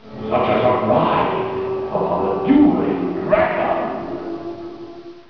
Sounds of Dueling Dragons
Some of the queue music has changed since these recordings were made